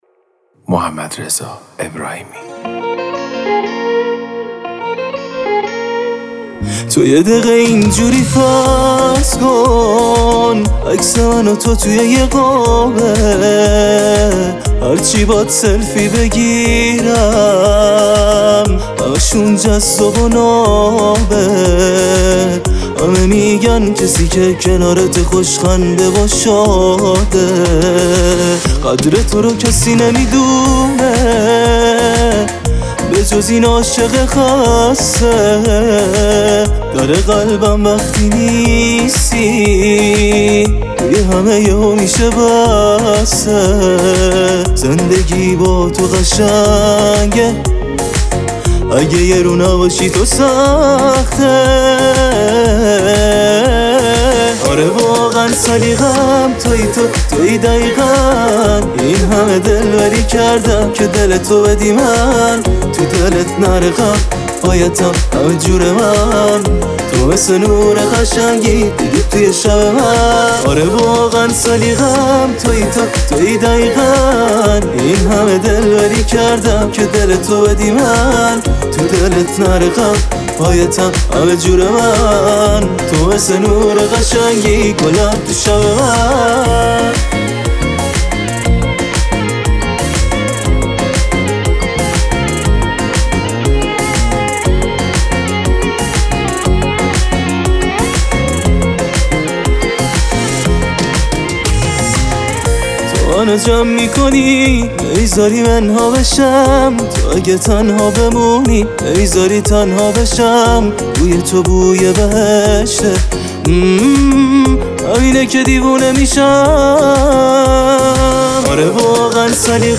دانلود اهنگ پاپ